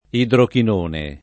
idrochinone [ idrokin 1 ne ] s. m. (chim.)